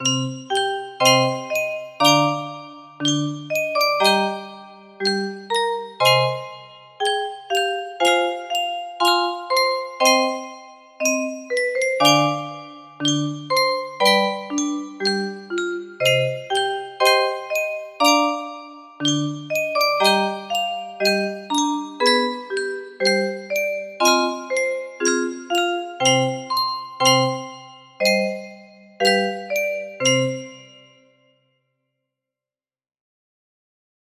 Aly2 music box melody